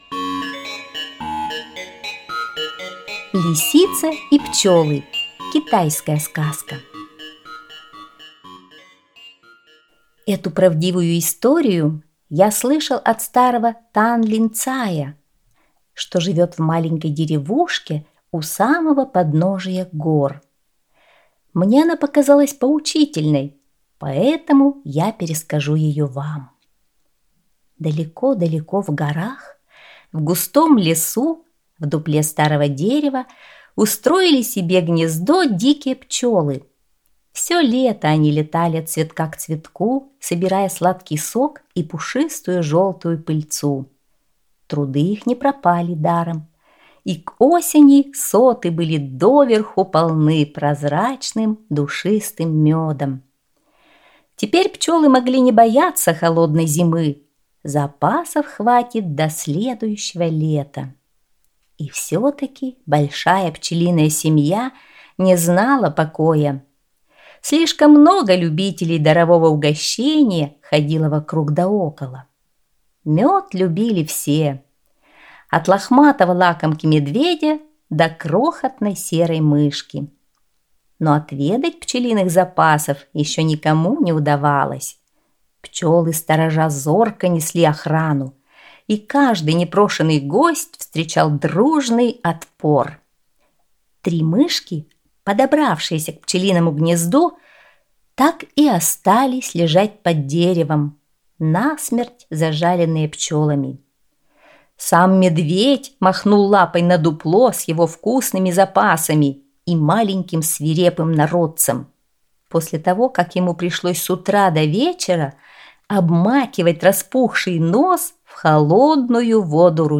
Лисица и пчёлы – китайская аудиосказка